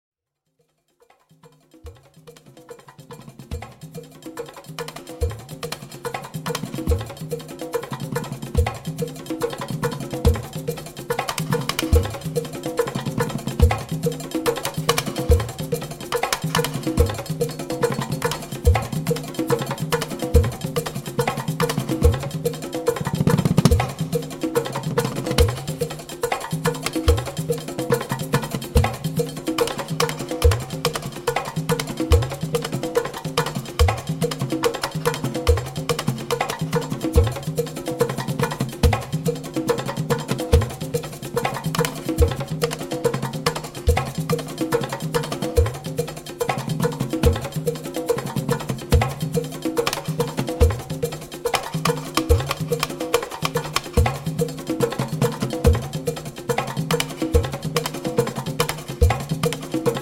A great balance of latin jazz styles.
tenor saxophone, flute, piccolo
piano, accordion